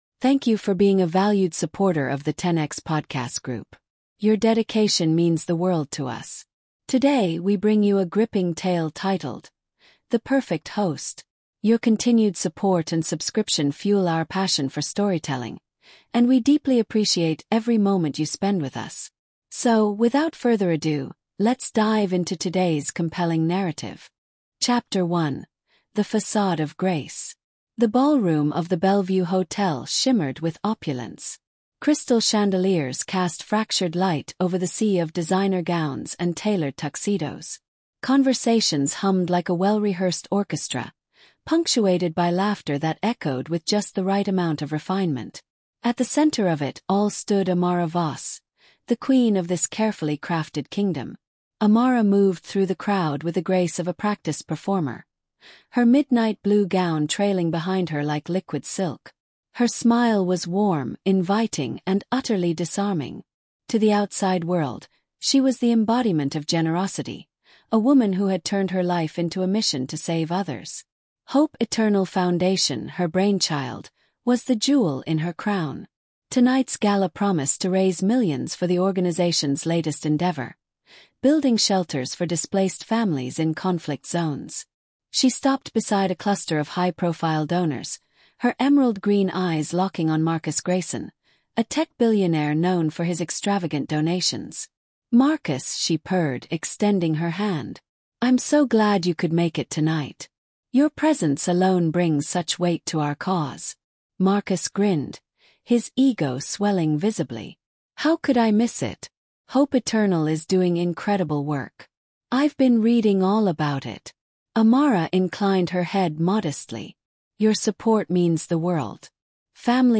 Dangerous Charity is a gripping storytelling podcast about the darker side of philanthropy. Amara Voss, a celebrated philanthropist, hides a sinister past and manipulates donors to fund her charity, all while secretly funneling money to her own shadowy agenda. When relentless investigator Ethan Kane begins to uncover the cracks in her empire, a high-stakes battle of wit, deceit, and survival ensues.